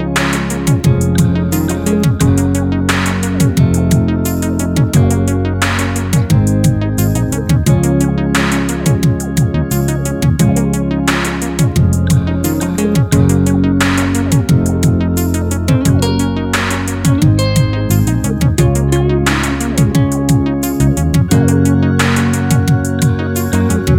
no Backing Vocals Reggae 4:30 Buy £1.50